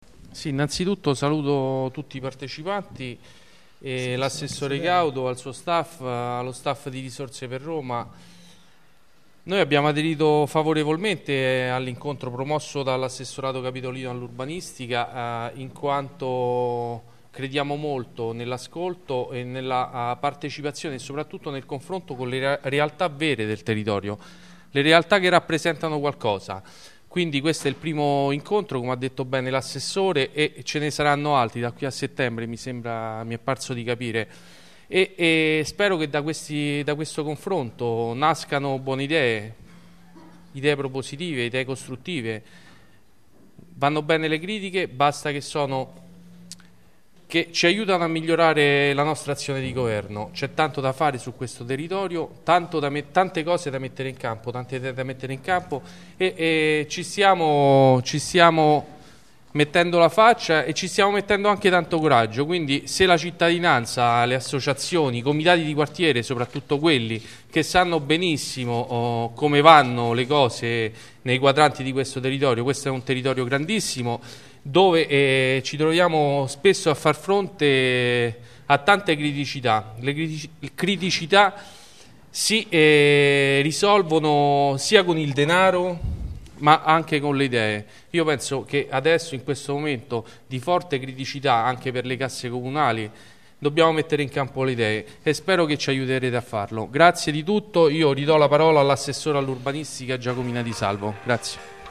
Registrazione integrale dell'incontro svoltosi il 9 giugno 2014 presso il Teatro del Lido in Via delle Sirene, 22
Tassone   Andrea Tassone, Presidente Municipio X